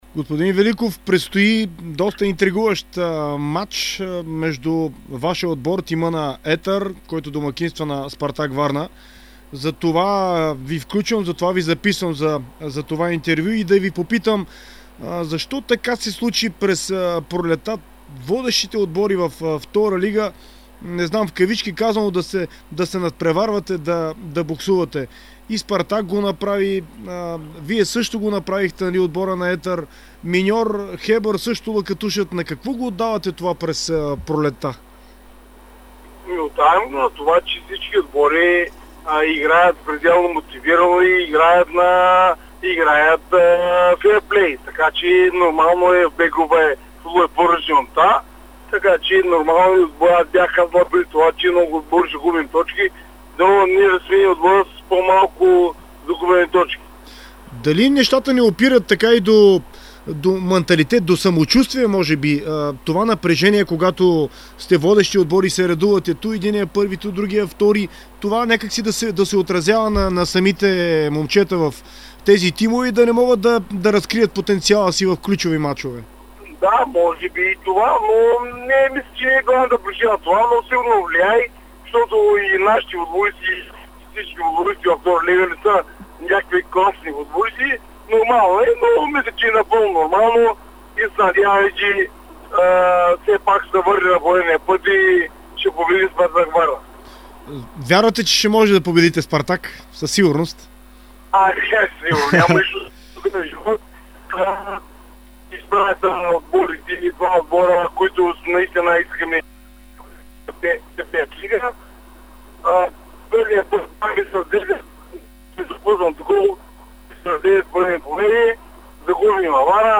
Чуйте цялото интервю в приложения звуков файл!